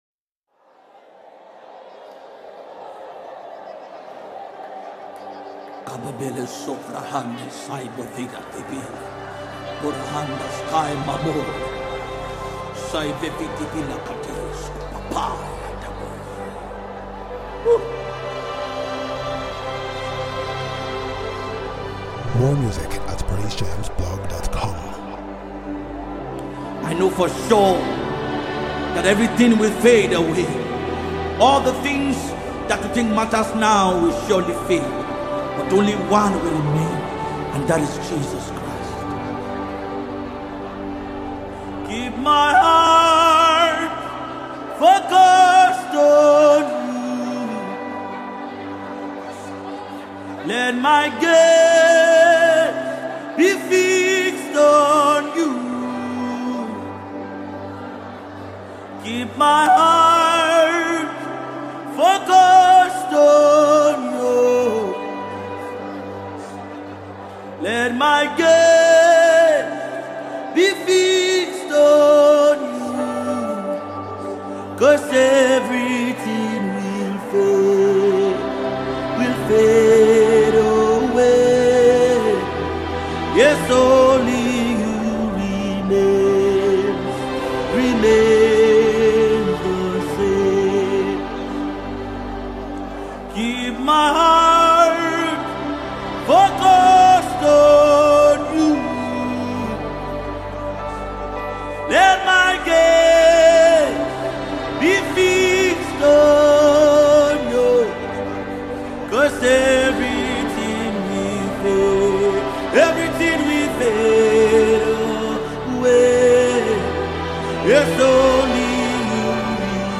Nigerian gospel music sensation
With heartfelt lyrics and powerful musical arrangements